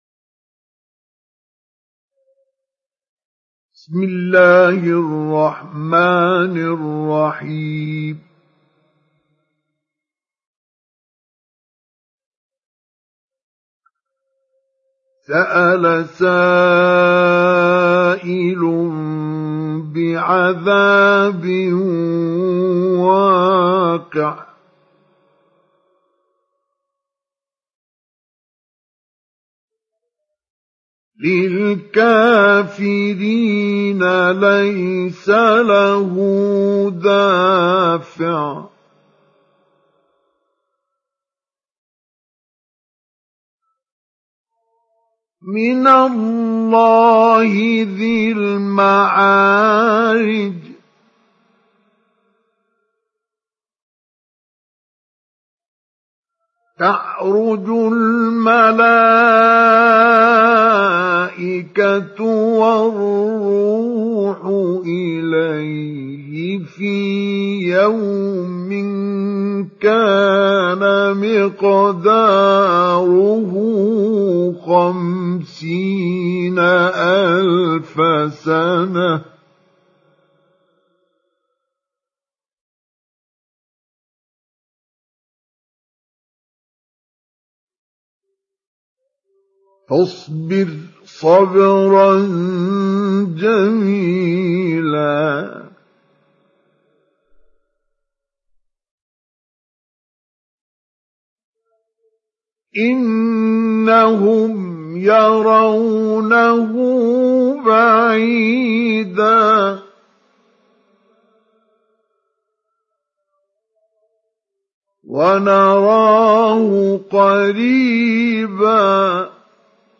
تحميل سورة المعارج mp3 بصوت مصطفى إسماعيل مجود برواية حفص عن عاصم, تحميل استماع القرآن الكريم على الجوال mp3 كاملا بروابط مباشرة وسريعة
تحميل سورة المعارج مصطفى إسماعيل مجود